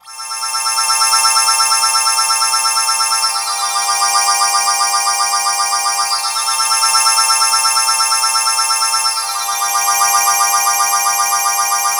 Harp Loopy.wav